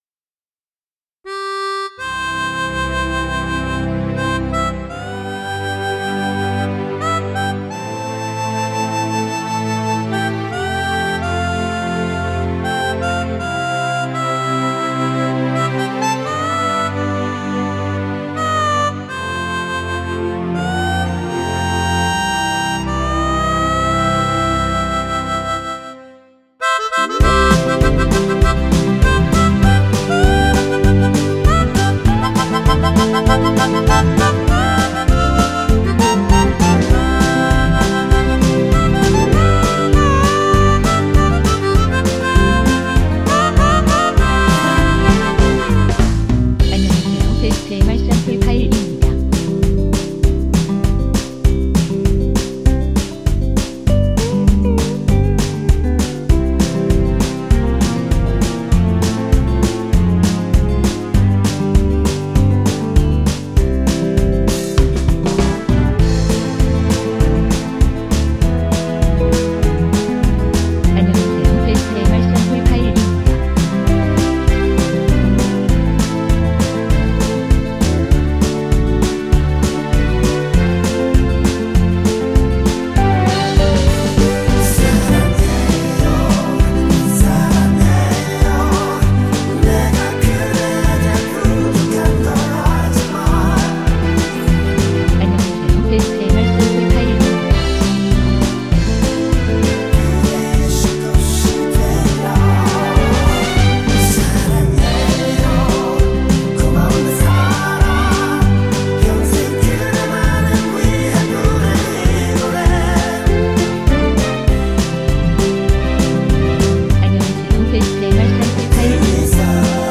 원키 코러스 포함된 MR입니다.(미리듣기 확인)
앞부분30초, 뒷부분30초씩 편집해서 올려 드리고 있습니다.